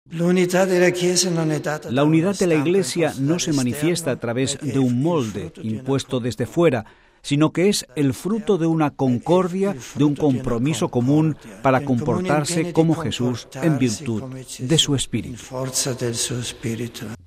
Sábado, 5 mar (RV).- Ayer por tarde, el Santo Padre, visitó el Seminario Romano Mayor, en la vigilia de la Fiesta de Nuestra Señora de la Confianza, patrona de este Instituto.